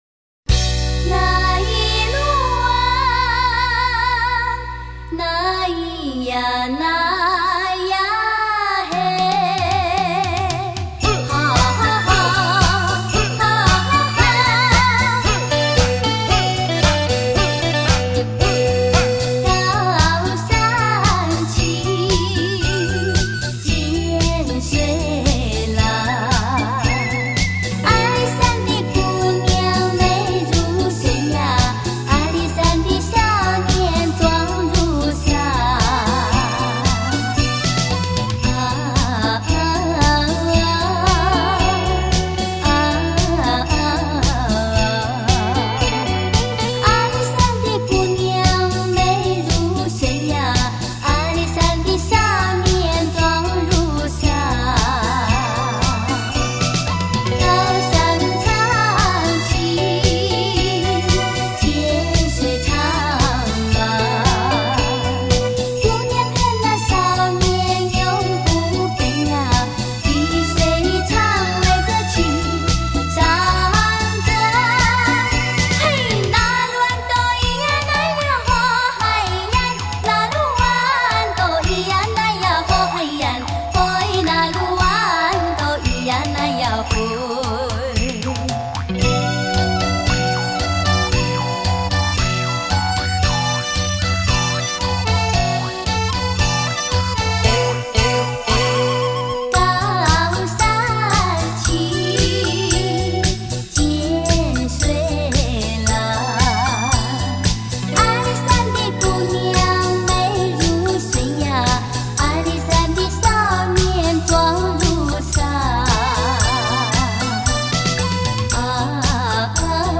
那歌声...那旋律...悠扬飘荡...